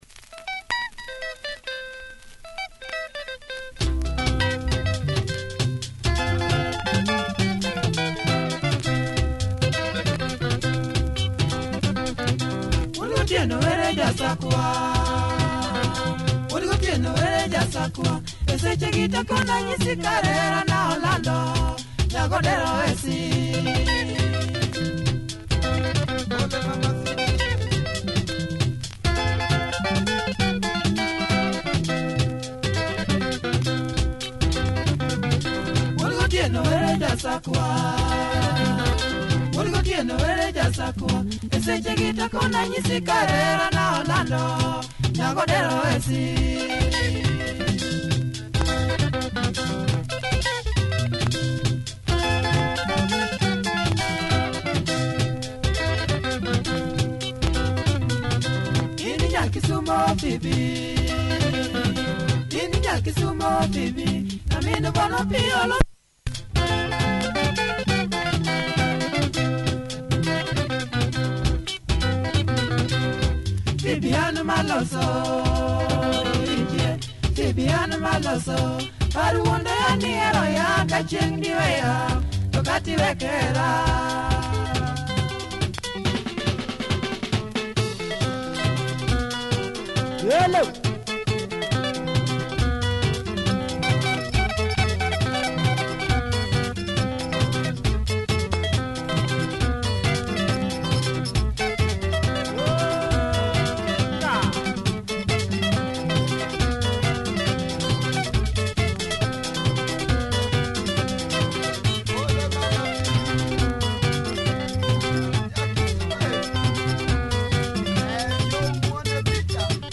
Nice LUO benga!